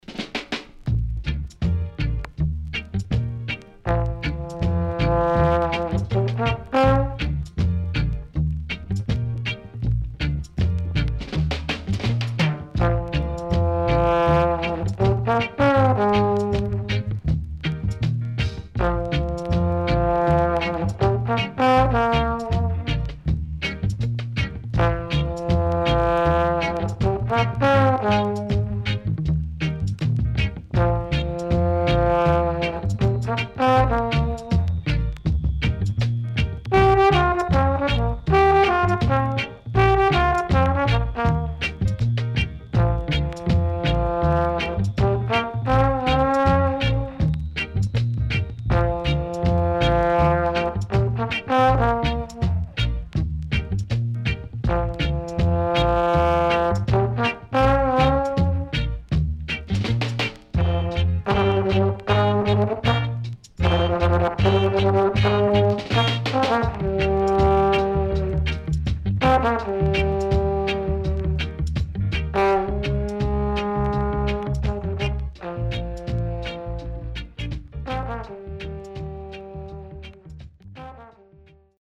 HOME > REGGAE / ROOTS  >  70’s DEEJAY  >  INST 70's
SIDE A:所々チリノイズがあり、少しプチノイズ入ります。